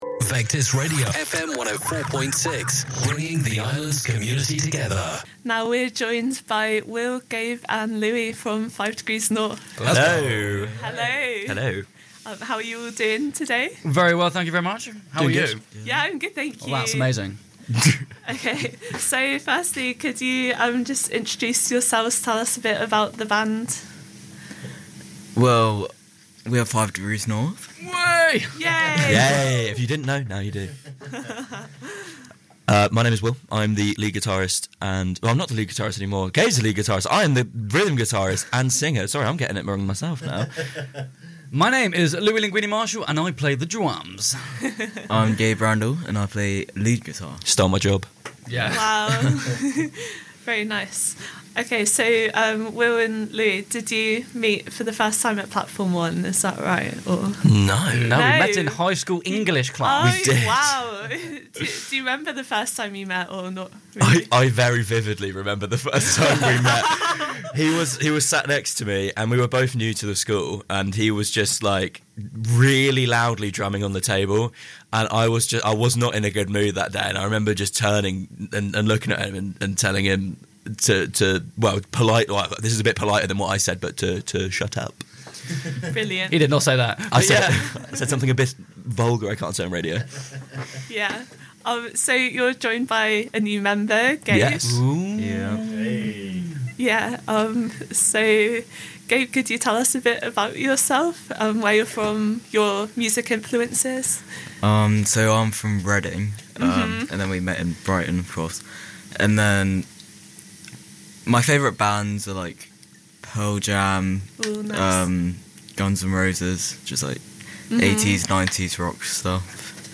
Isle of Wight and Brighton-based rock band 5 Degrees North came in to talk about their new single 'Red River'.